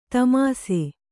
♪ tamāse